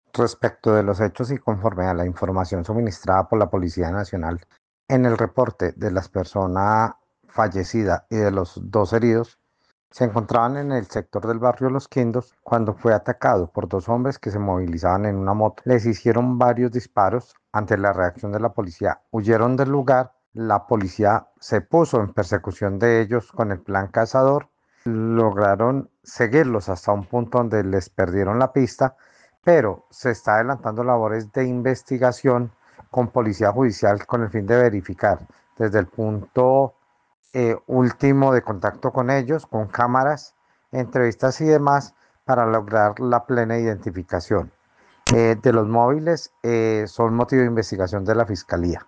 Secretario de Gobierno de Armenia, Carlos Arturo Ramirez